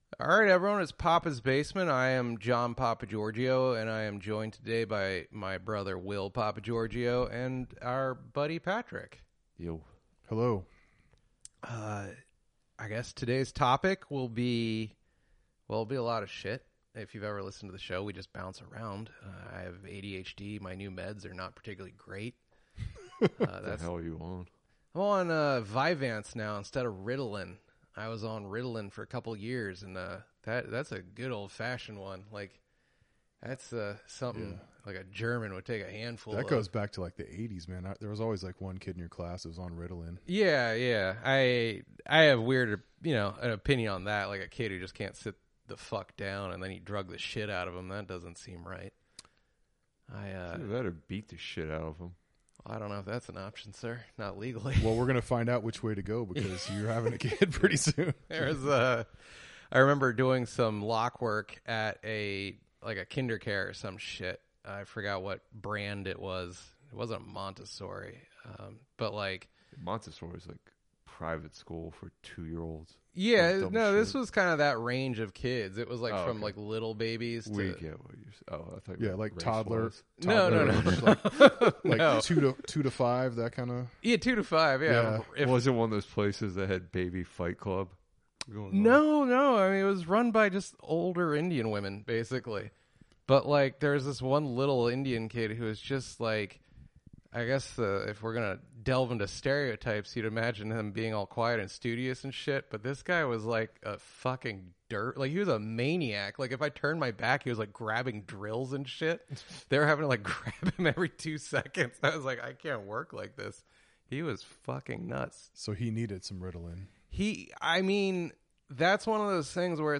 DC's Premier Comedy Podcast and Radio Show.